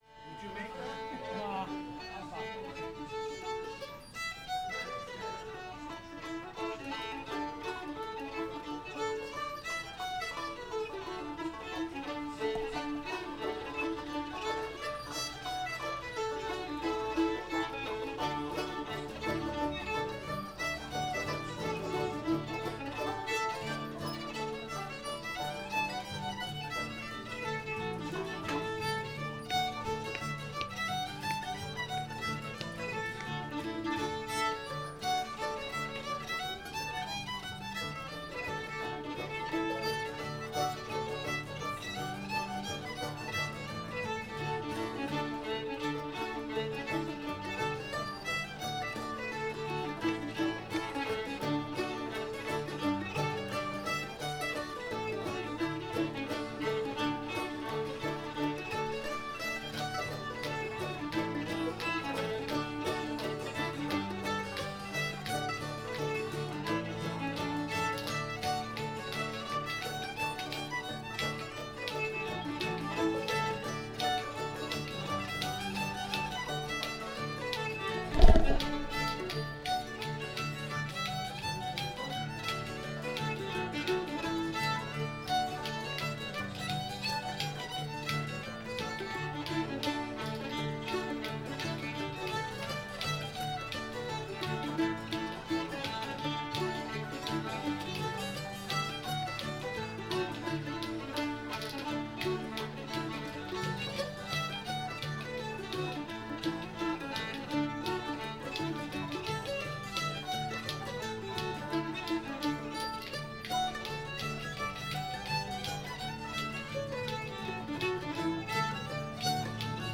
old virginia reel [D]